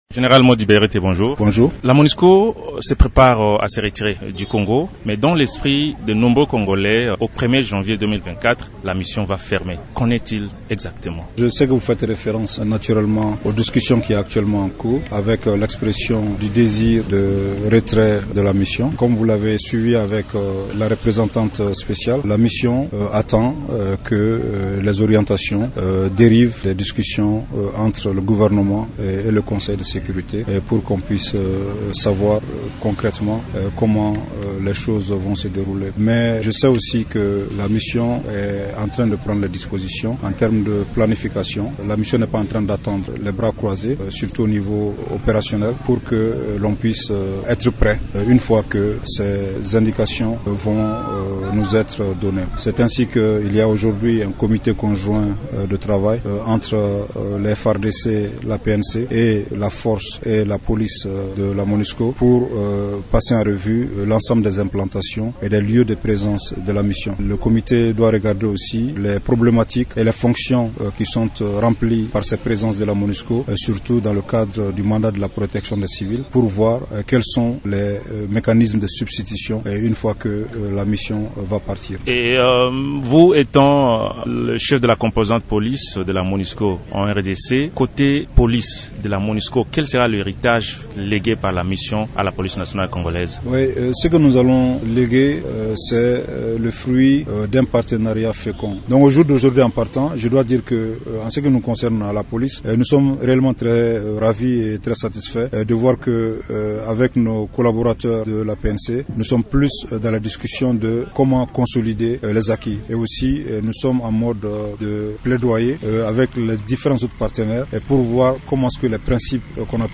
Invité de Radio Okapi ce mercredi, le général Mody Berethe parle de l’héritage que la police de la MONUSCO va léguer à la PNC.